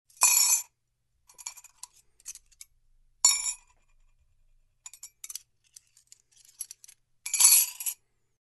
Звуки ложки
Звук ложки в стеклянном стакане дзинь